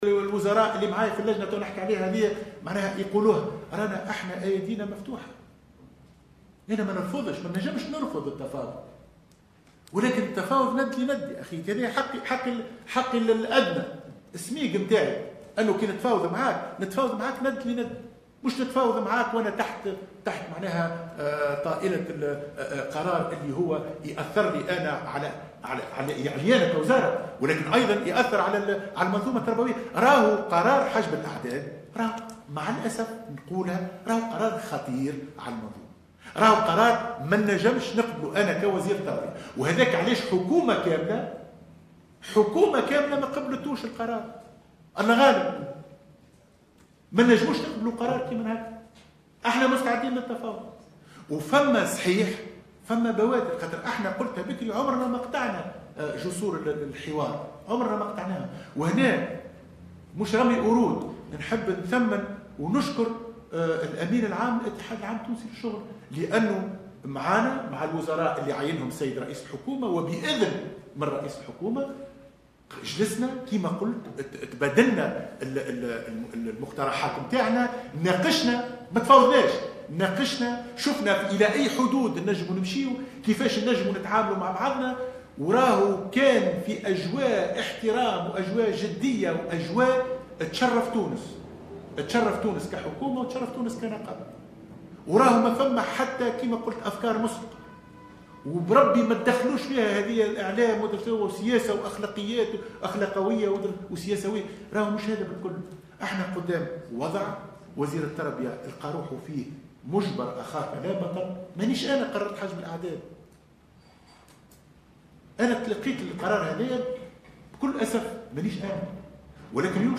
أكد وزير التربية، حاتم بن سالم، في جلسة استماع برلمانية اليوم الأربعاء، لمناقشة أزمة حجب أعداد التلاميذ، ان التشاور سيفضي إلى نتائج في القريب العاجل، بحسب تعبيره، متوجها بنداء للأساتذة لتسليم الأعداد.